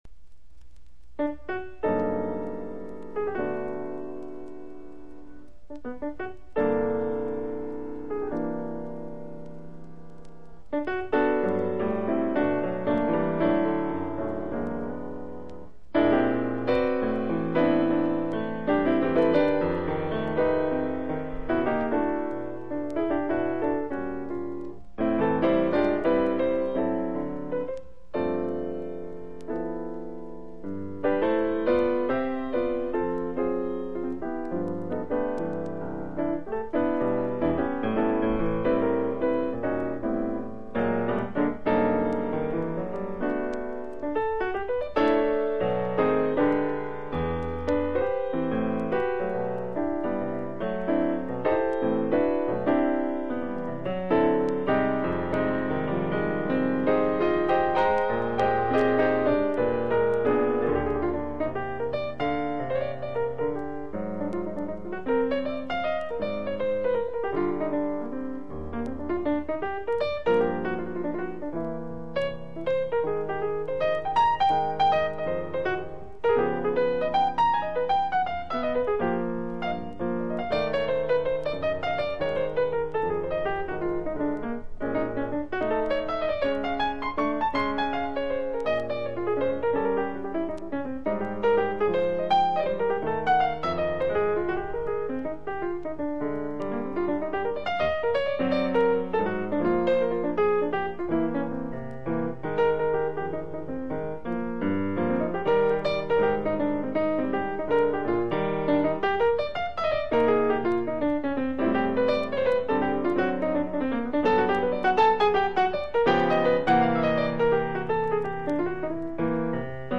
（プレスによりチリ、プチ音ある曲あり）※曲名をクリックすると試…